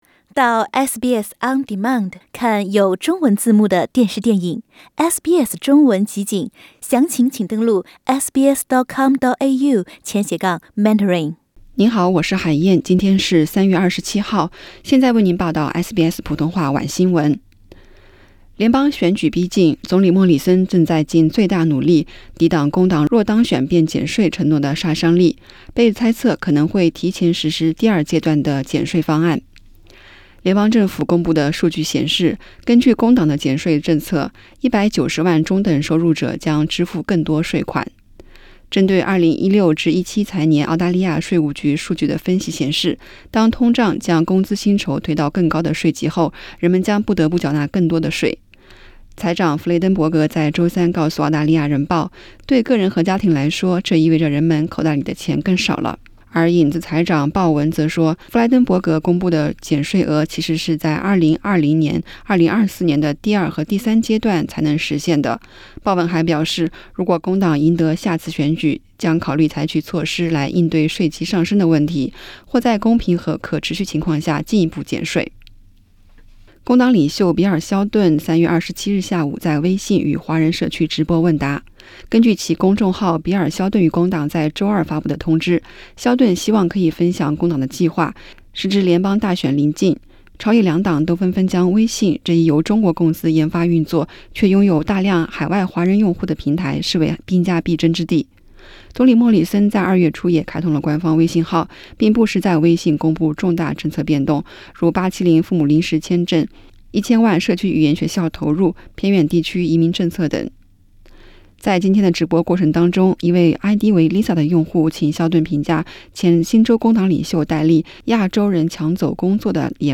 SBS晚新闻（3月27日）